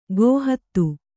♪ gōhattu